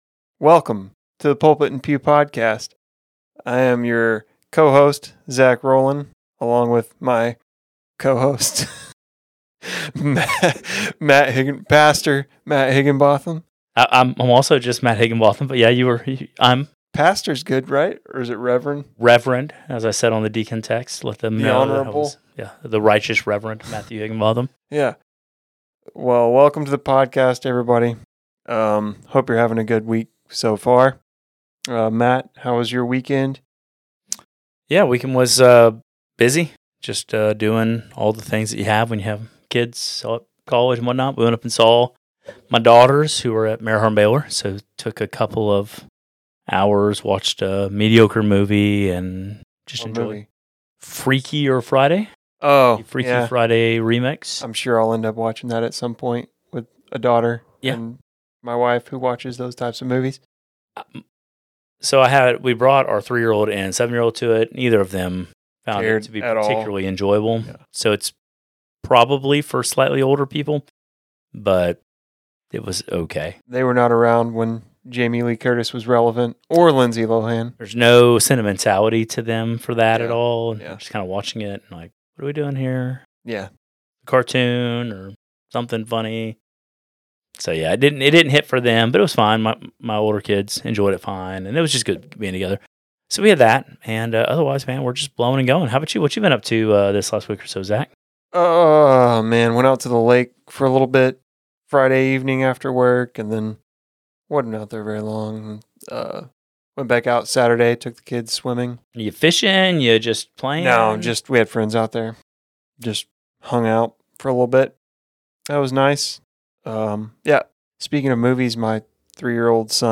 We will discuss “designer babies”, IVF, contraception, and adoption in this wide ranging conversation.